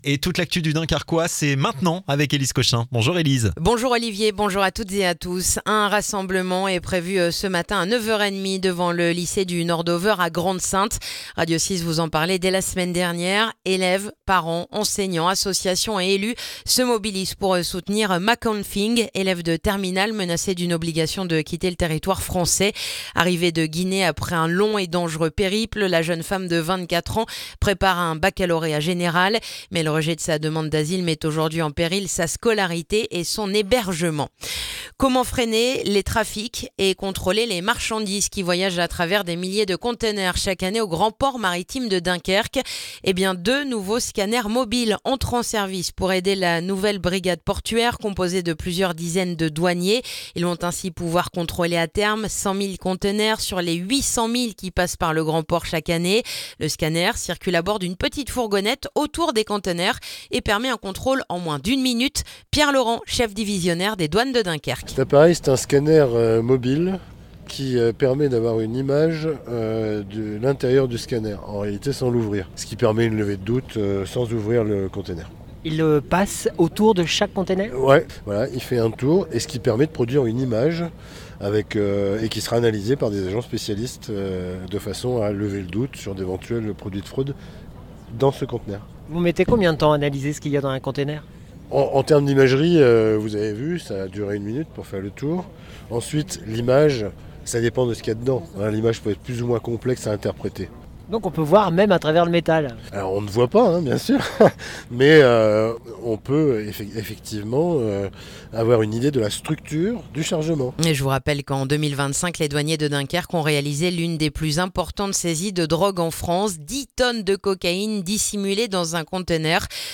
Le journal du jeudi 12 février dans le dunkerquois